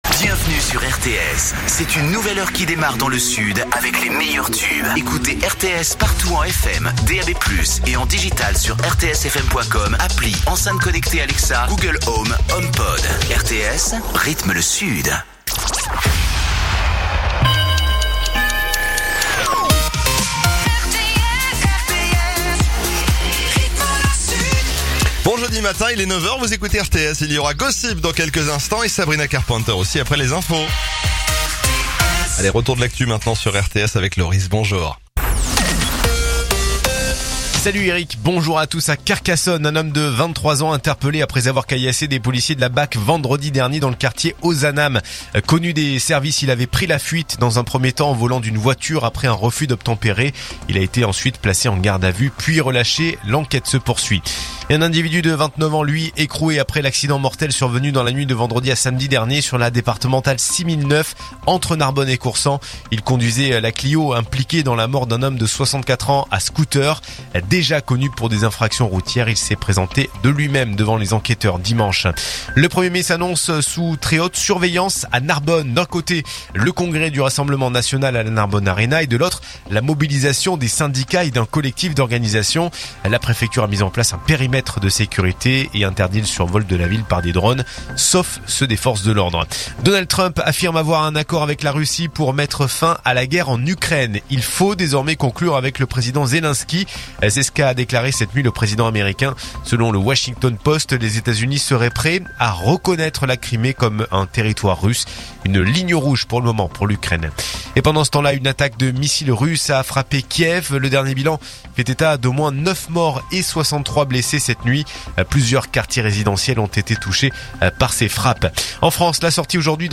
info_narbonne_toulouse_363.mp3